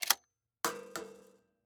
weapon
Bullet Shell 12 Gauge Eject Shells On Metal Surface 1